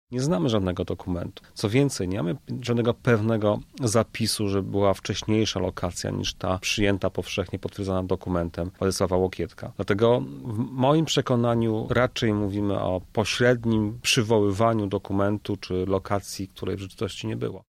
historyk z Katolickiego Uniwersytetu Lubelskiego